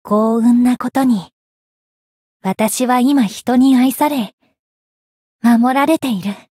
灵魂潮汐-密丝特-圣诞节（送礼语音）.ogg